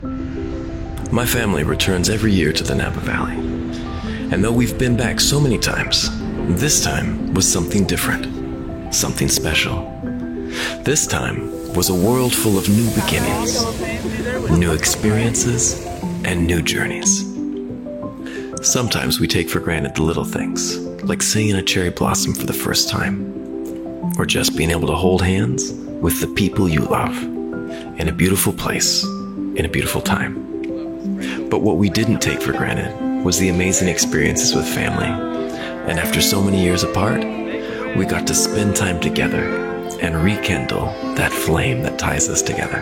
Giọng nam người Mĩ
Quảng cáo (story-telling style)